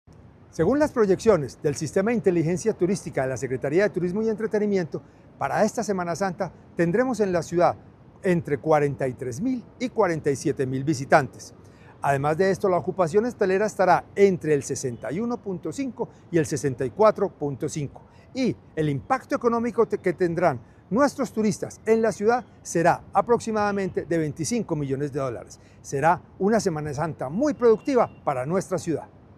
Declaraciones secretario de Turismo y Entretenimiento, José Alejandro González.
Declaraciones-secretario-de-Turismo-y-Entretenimiento-Jose-Alejandro-Gonzalez..mp3